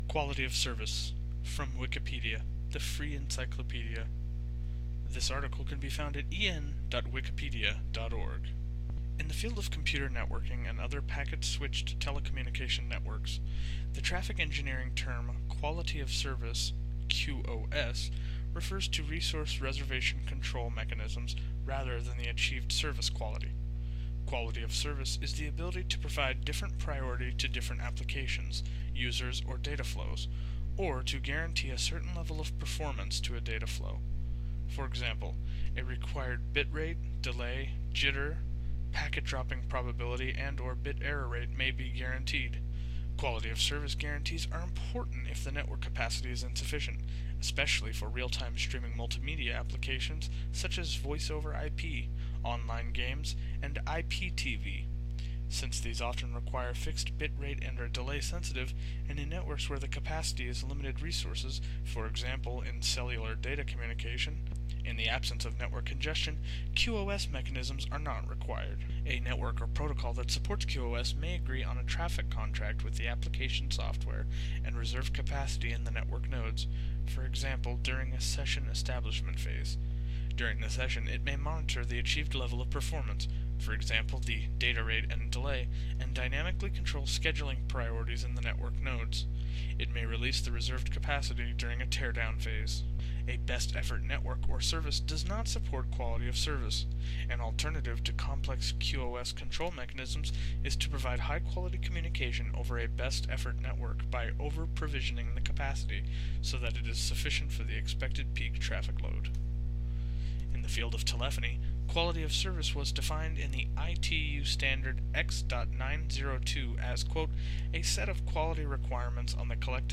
قالب:GFDL-user-en-with-disclaimers قالب:Spoken article
|accent= U.S. California
Quality_of_Service_Spoken.ogg.mp3